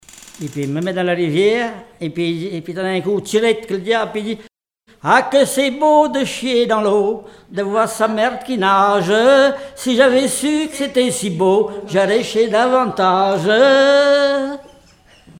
Genre brève
Chansons traditionnelles
Pièce musicale inédite